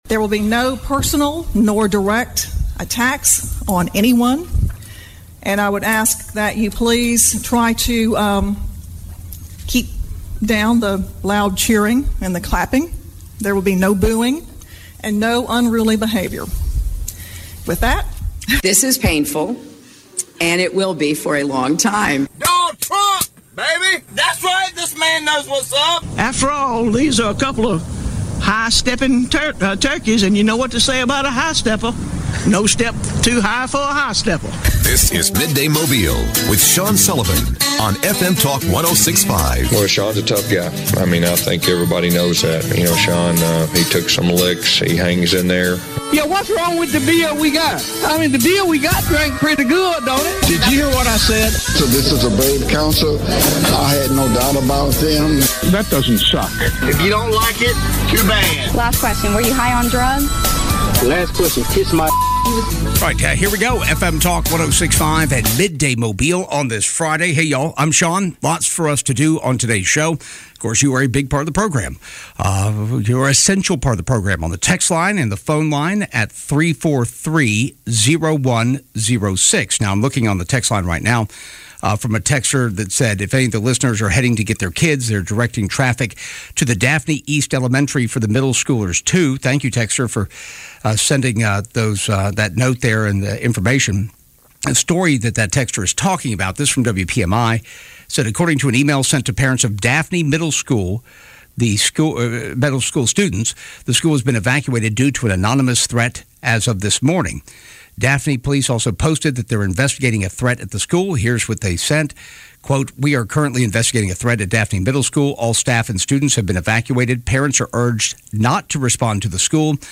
Midday Mobile - Breaking down the Pelosi hammer video and Congressman Jerry Carl calls in from DC - January 27 2023